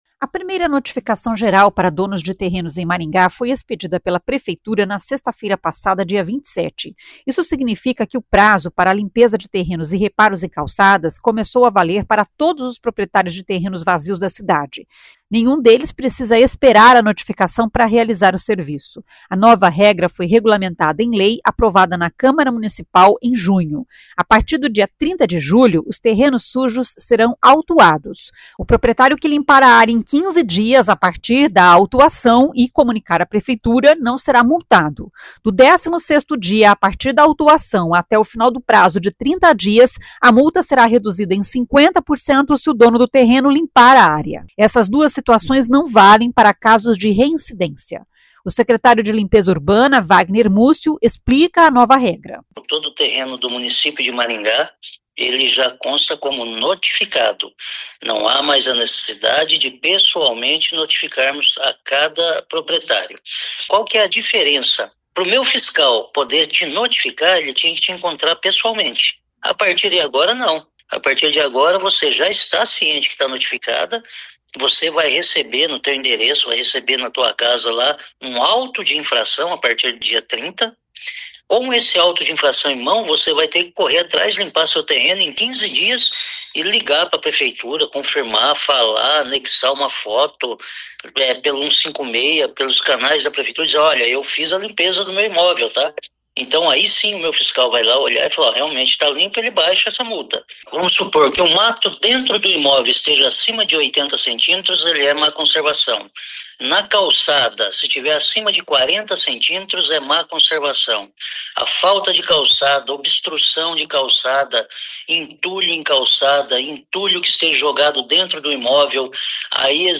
O secretário de Limpeza Urbana, Vagner Mussio, explica a nova regra.